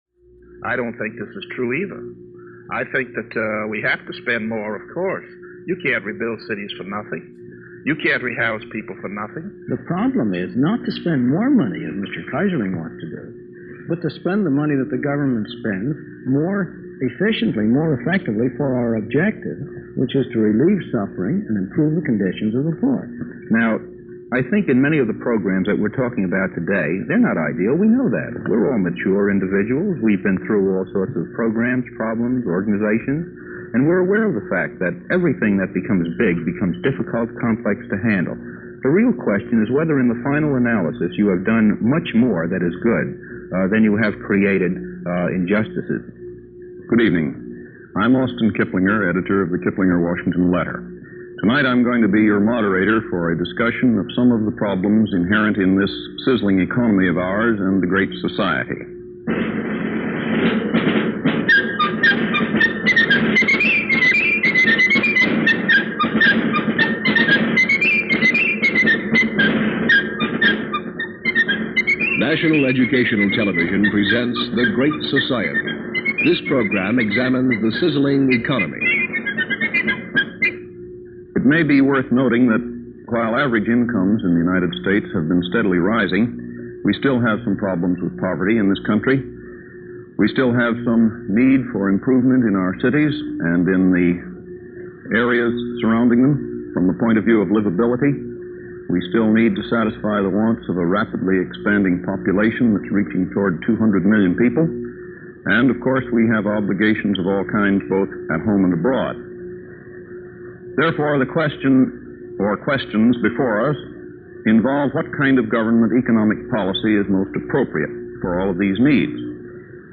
Forerunner to PBS , NET ran the program and it was broadcast on June 29, 1966.